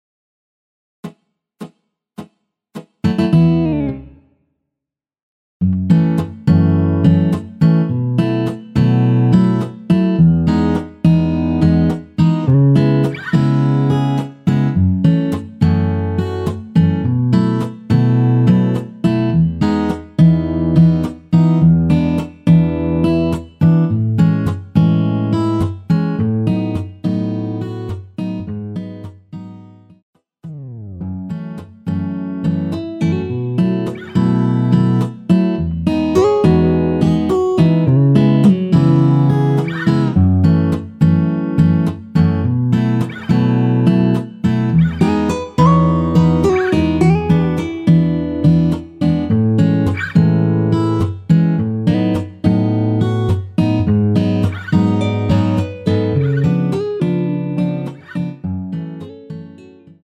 전주 없이 시작 하는 곡이라 인트로 1마디 만들어 놓았습니다.~ (미리듣기 참조)
앞부분30초, 뒷부분30초씩 편집해서 올려 드리고 있습니다.
중간에 음이 끈어지고 다시 나오는 이유는